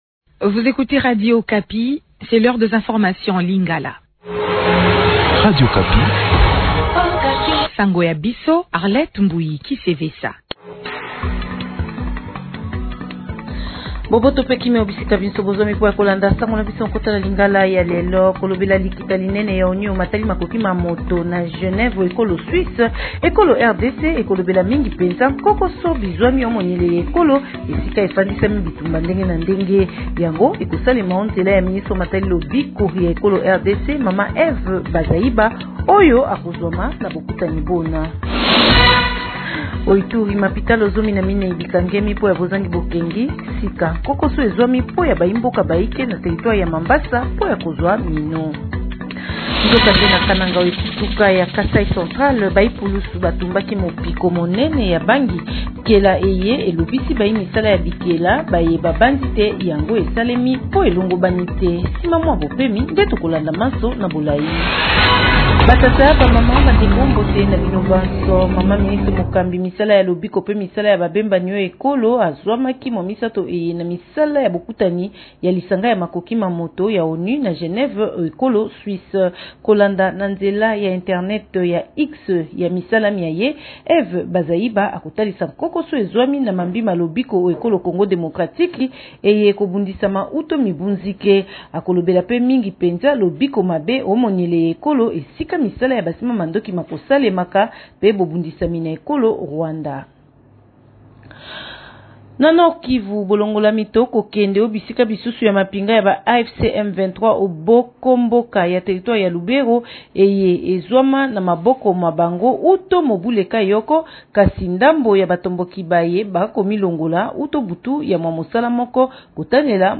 Journal Lingala Matin